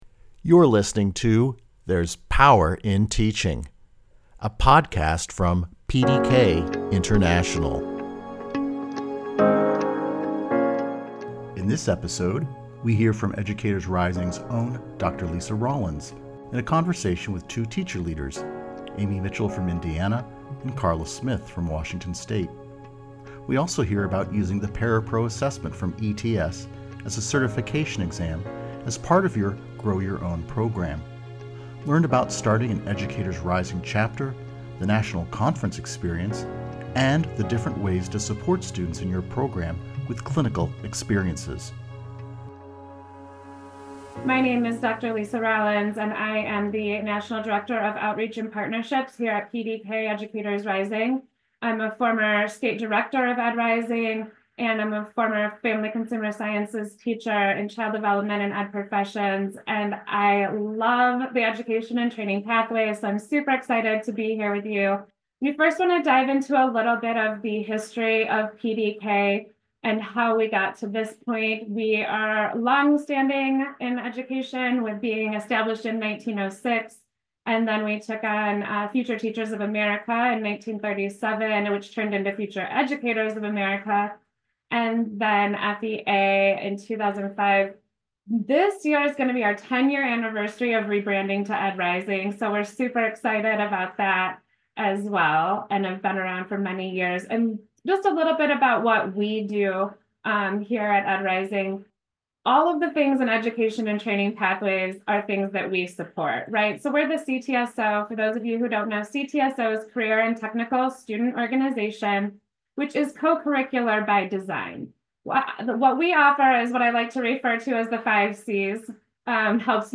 This interview is an except from our 6 November 2024 Plan Ahead webinar which is available in its entirety on our webinars page.